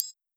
Peep.wav